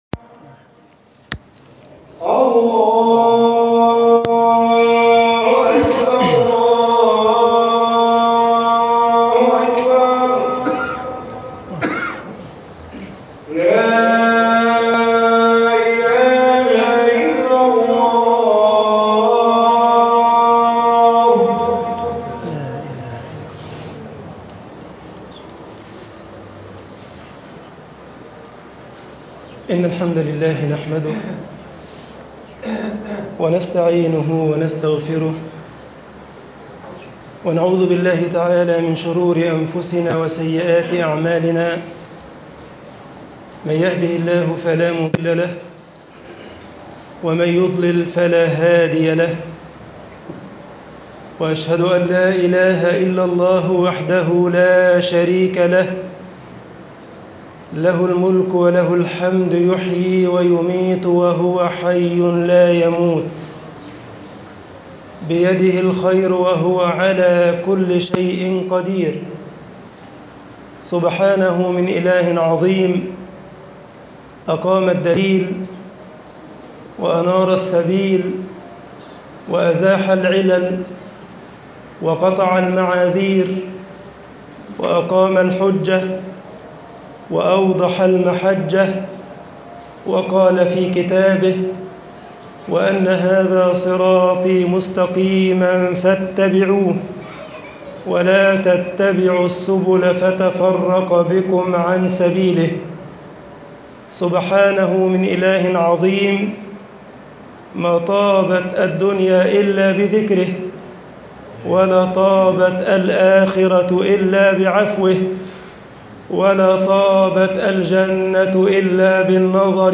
خطب الجمعة - مصر الإسلام والعلم طباعة البريد الإلكتروني التفاصيل كتب بواسطة